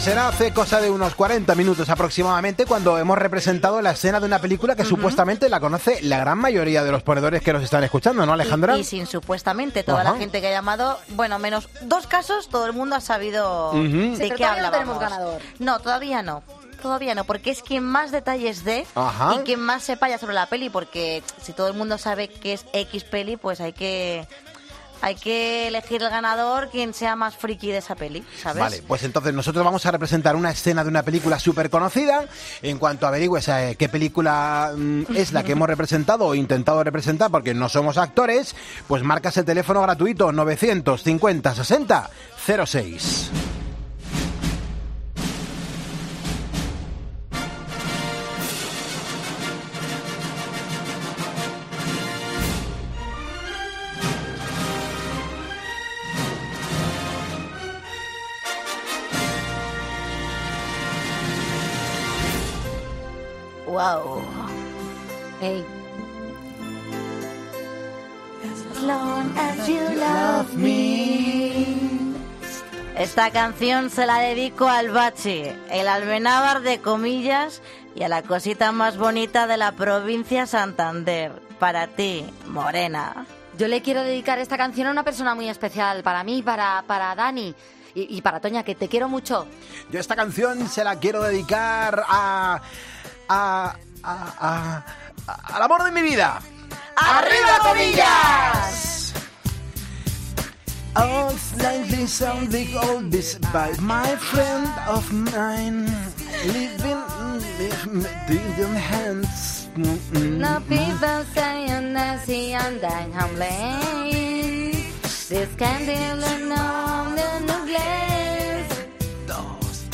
Descubre la peli... el equipo de PLC son actores de doblaje por un día
AUDIO: Los viernes el equipo de Poniendo Las Calles interpreta una escena de una película y tú tienes que adivinar cuál es.